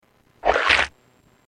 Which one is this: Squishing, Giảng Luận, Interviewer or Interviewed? Squishing